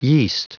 Prononciation du mot yeast en anglais (fichier audio)
Prononciation du mot : yeast